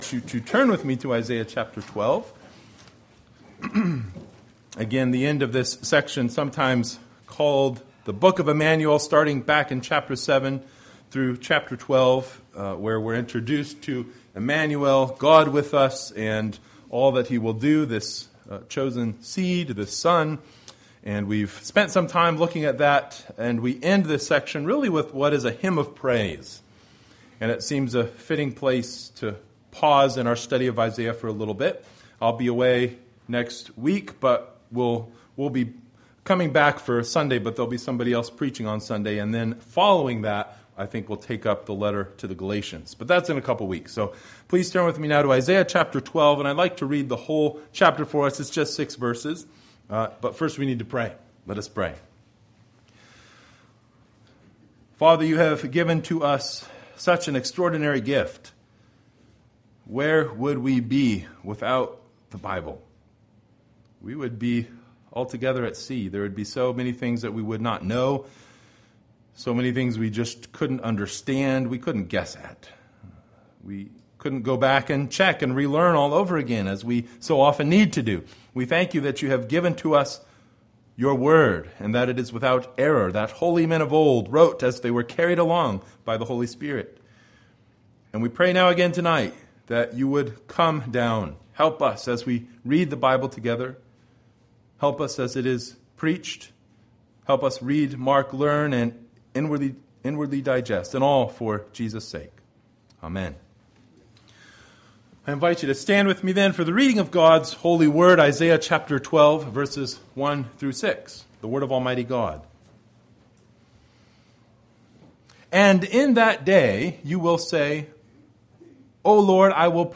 Isaiah Passage: Isaiah 12:1-6 Service Type: Sunday Evening Bible Text